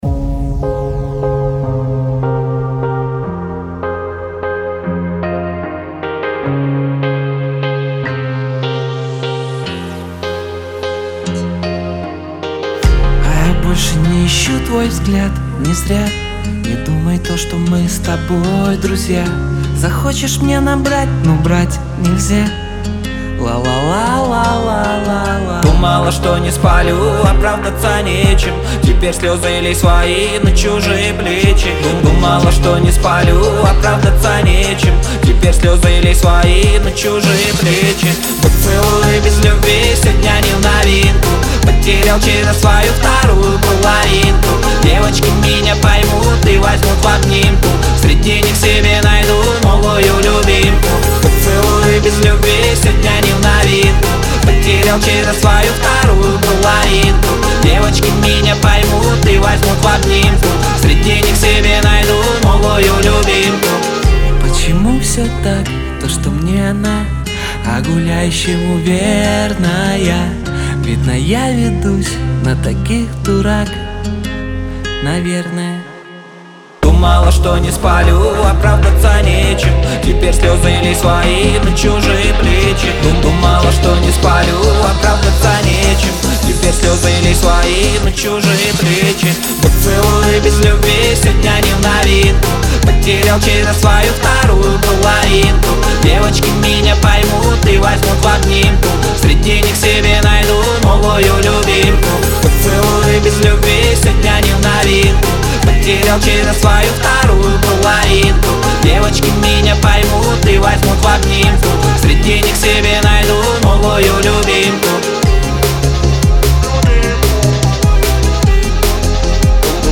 • Категория: Русские песни